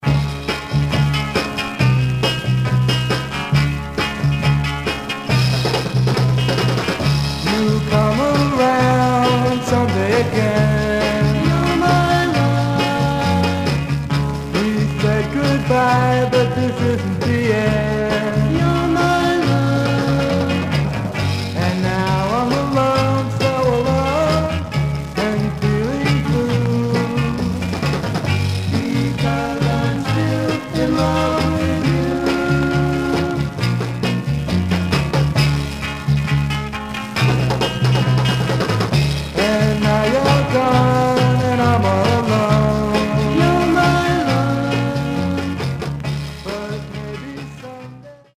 Surface noise/wear
Mono
Garage, 60's Punk